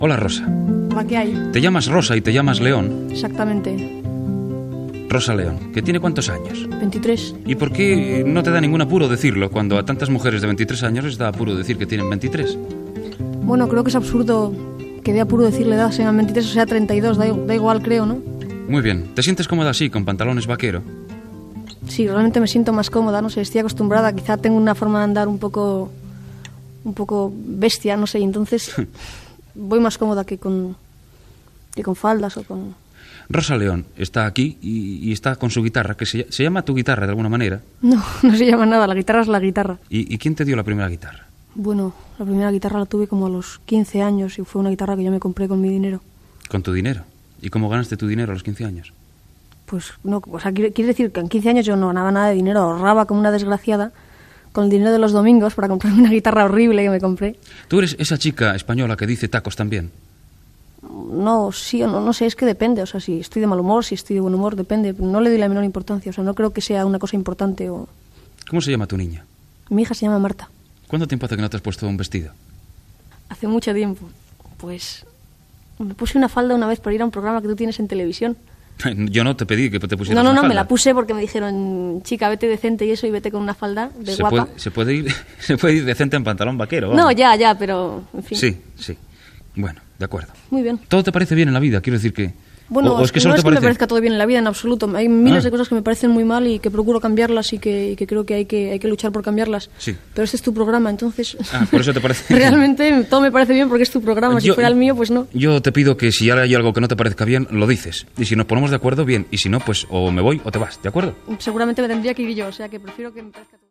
Entrevista a la cantant Rosa León
Entreteniment